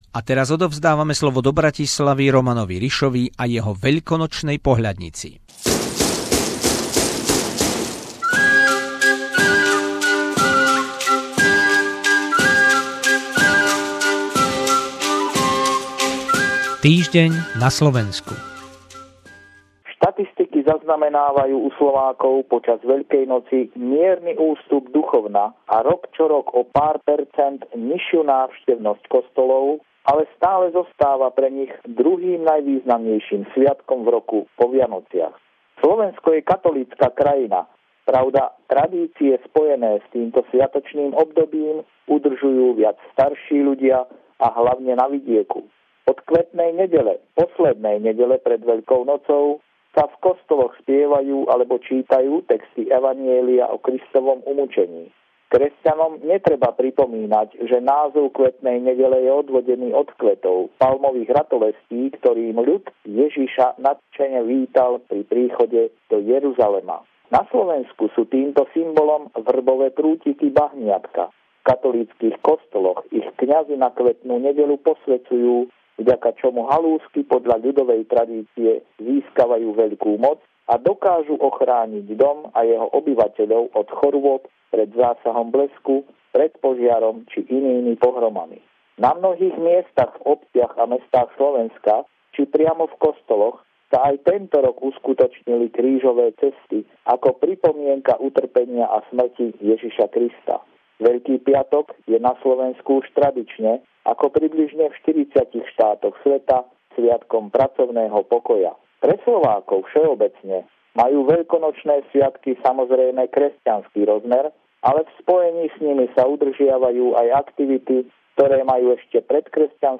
Nie celkom pravidelný telefonát týždňa